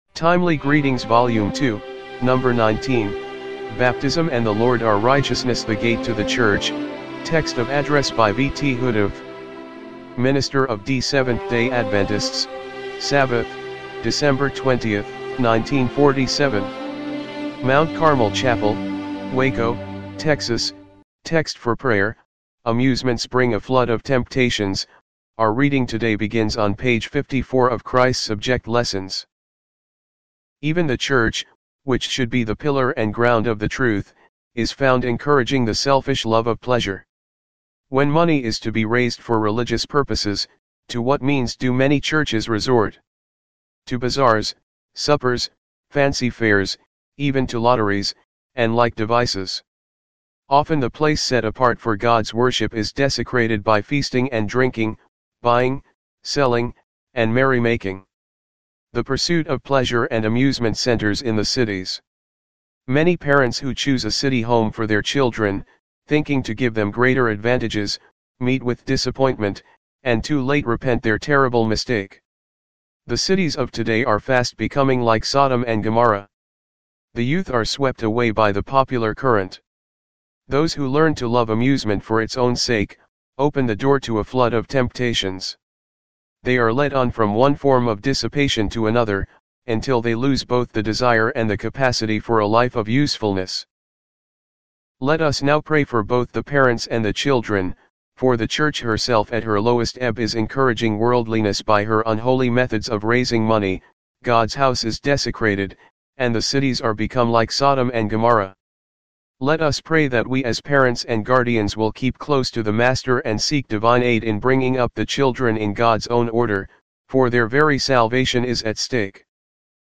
timely-greetings-volume-2-no.-19-mono-mp3.mp3